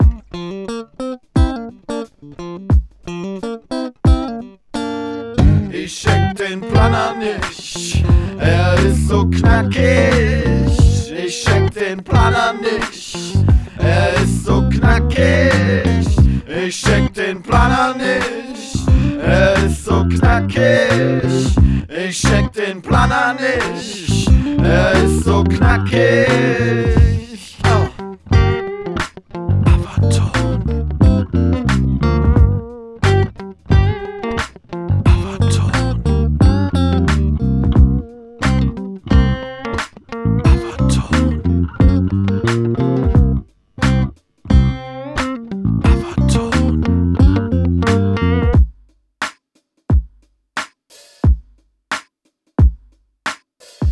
Zum Vergrößern anklicken.... quick - remaster your_browser_is_not_able_to_play_this_audio das problem sind die hohen mitten und höhen. da fehlt was bei deiner version von.